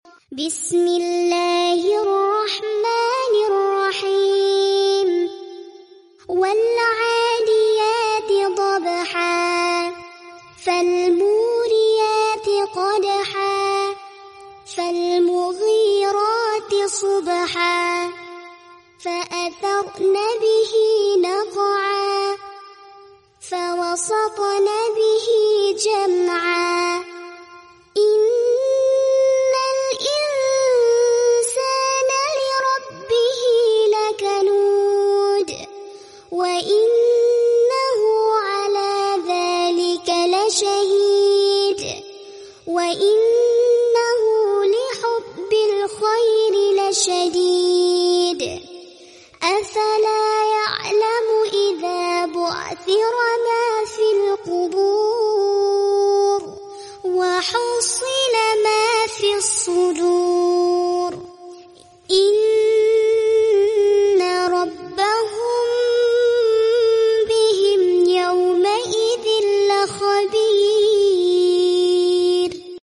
Murottal alquran bacaan anak.